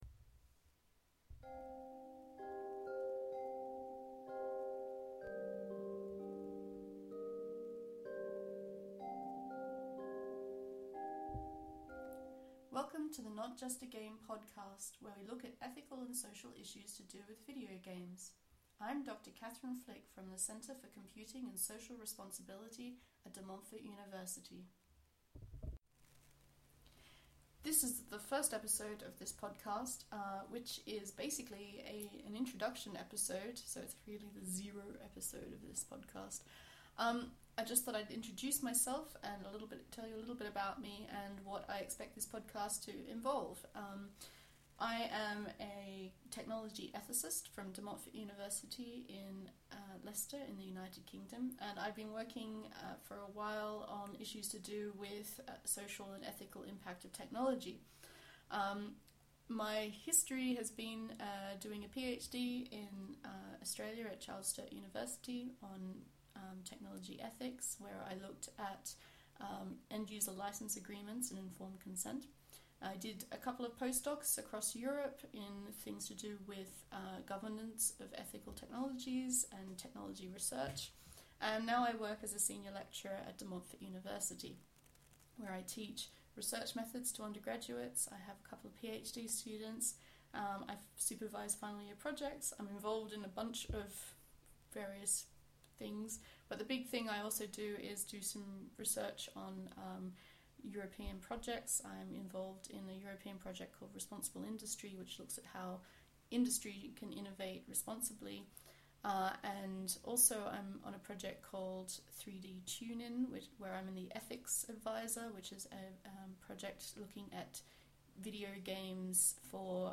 Please be gentle, I am working with new equipment and a new style of media! But any technical feedback welcome (I think I’ve solved the crackling in the microphone now though!).